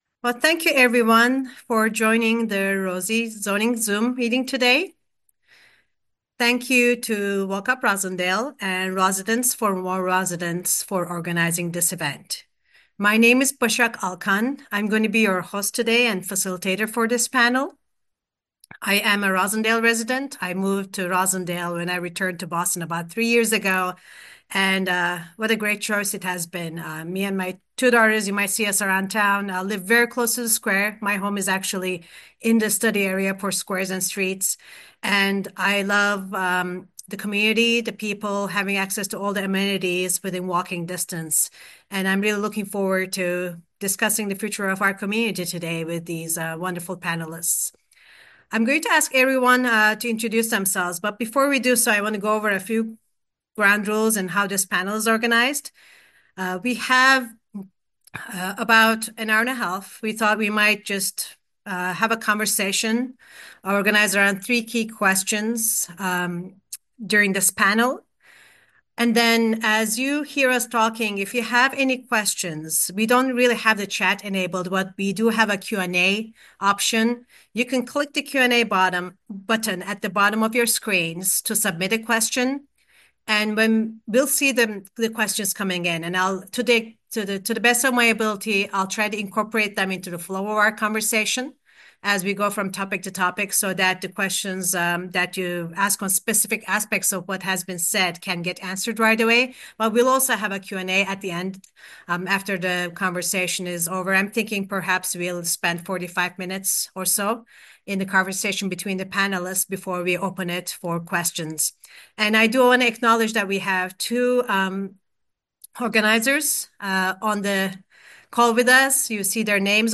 Last night, over 70 people joined us live to listen to a rich and lively discussion with an expert panel of urban planners, local officials, and housing advocates and dive deep into the new Squares + Streets zoning for Roslindale – what will this mean for the community?